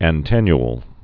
(ăn-tĕnyl)